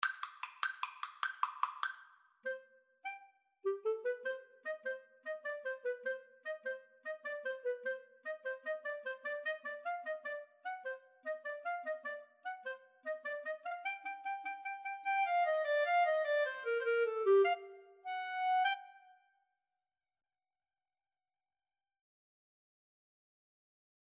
3/8 (View more 3/8 Music)
Classical (View more Classical Clarinet Duet Music)